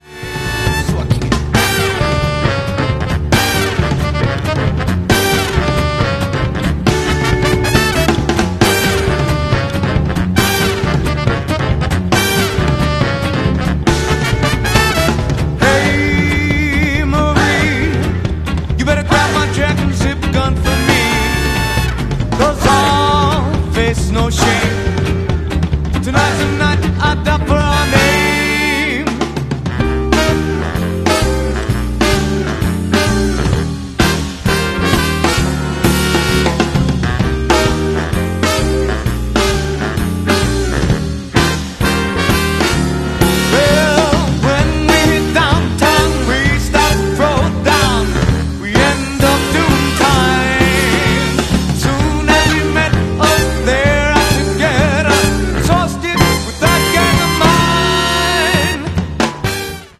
How Would A Ford Fusion Sound Straight Piped?